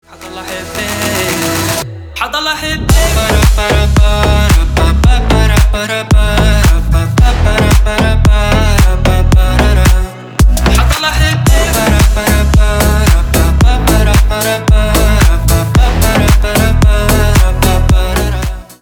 Ремикс
клубные
громкие